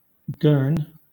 Ääntäminen
Ääntäminen Southern England
IPA : /ɡɜː(ɹ)n/